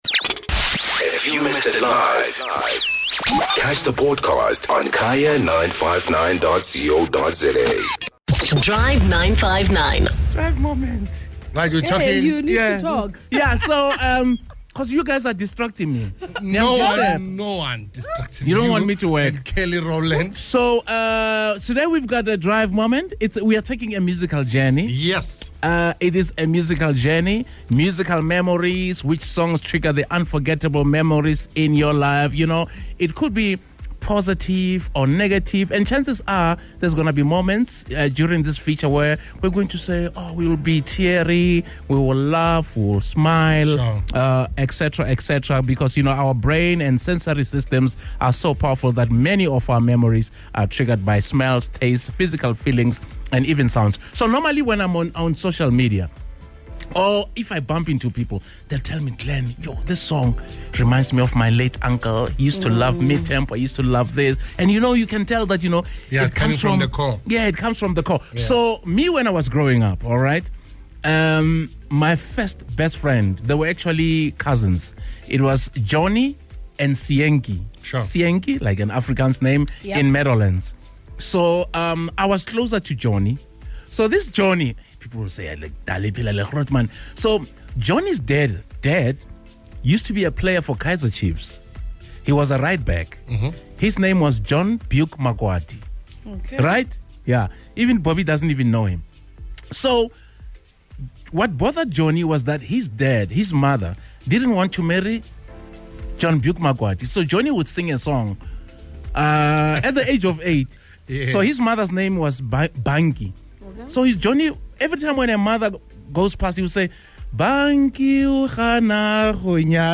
The Drive 959 team and listeners share their lifetime musical memories.